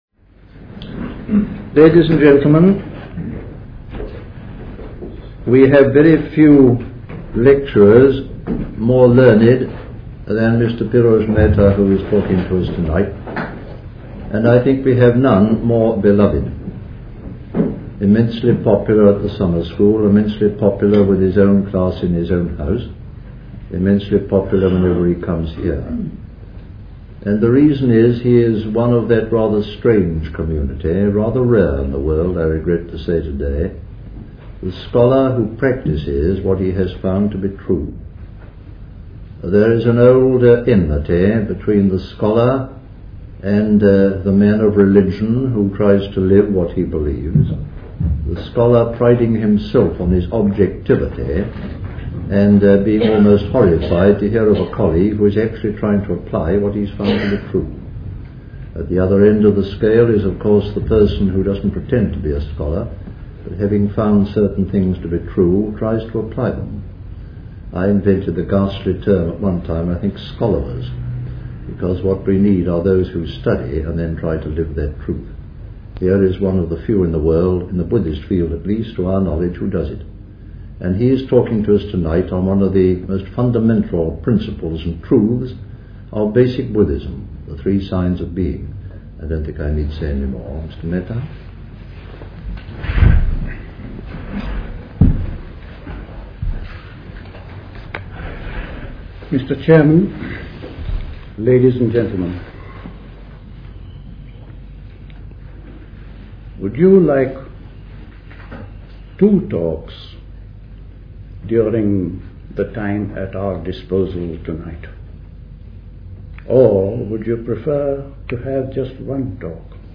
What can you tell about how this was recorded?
at High Leigh Conference Centre, Hoddesdon, Hertfordshire in August 1969